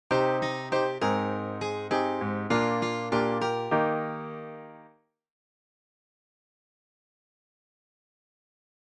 For the second phrase, I choose a higher chord tone to start on as I play that first Cm chord. I’m starting on a G note, and then finding a way to still follow that basic line drawing.